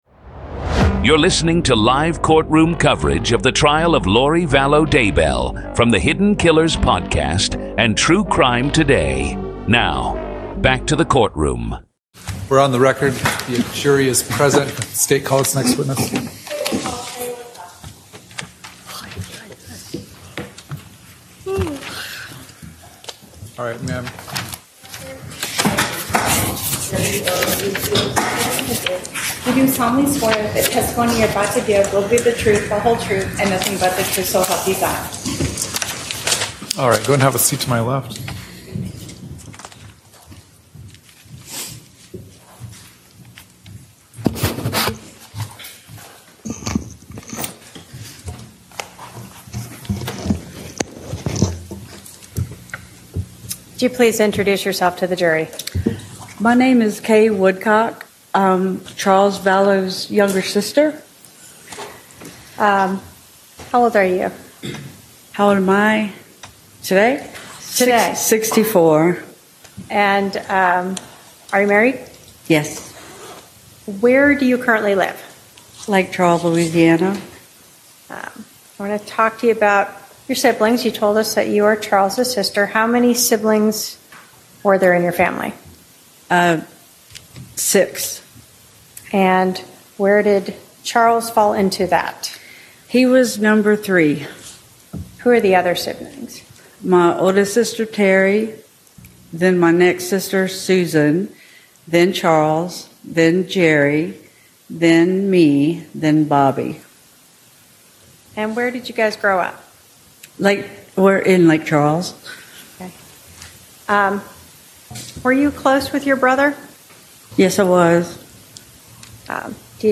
Today on Hidden Killers, we bring you the full, raw, unfiltered courtroom coverage from Day 4 of Lori Vallow Daybell’s murder trial in Arizona—exactly as it happened.
And through it all, you’ll hear Lori herself—calm, controlled, representing herself, cross-examining her own family, and showing little to no emotion as her beliefs, her behavior, and her potential motives are laid bare. This is the complete courtroom audio from April 10, 2025 —no edits, no filters, just the raw reality of one of the most bizarre and disturbing murder trials in modern history.